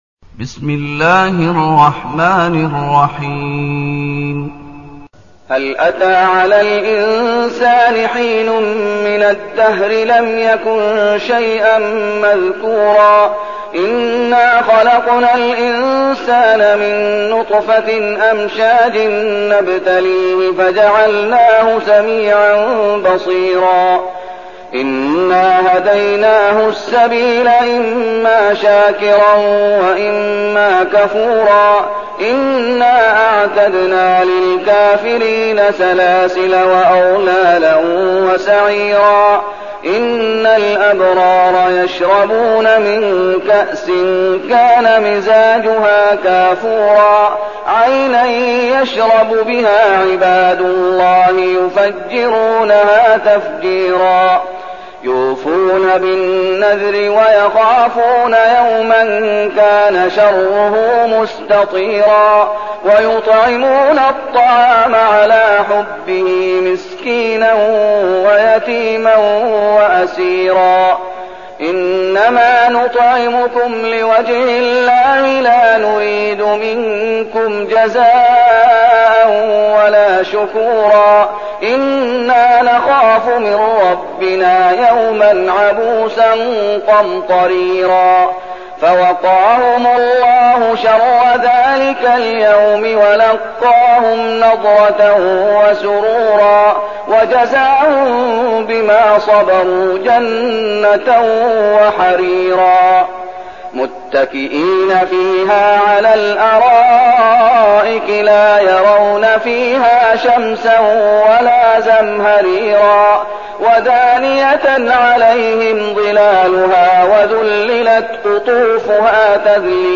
المكان: المسجد النبوي الشيخ: فضيلة الشيخ محمد أيوب فضيلة الشيخ محمد أيوب الإنسان